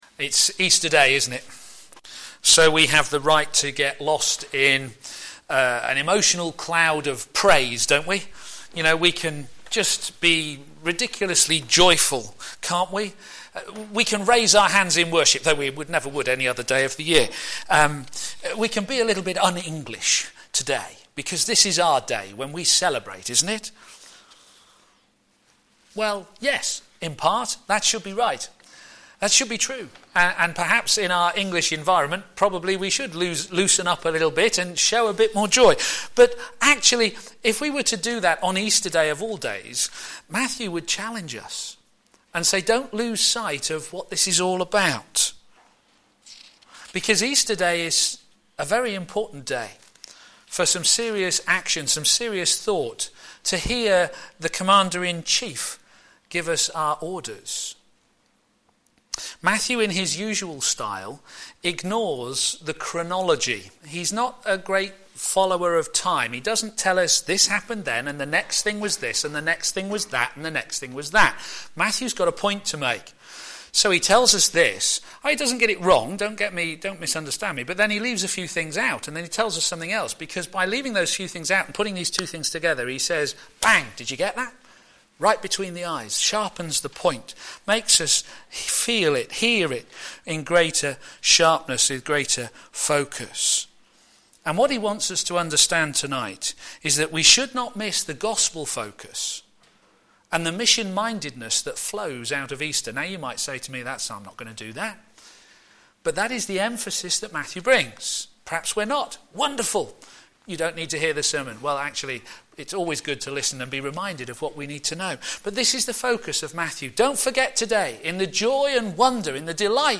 Media Library Media for p.m. Service on Sun 20th Apr 2014 18:30 Speaker
Behold your King! Who commands! Sermon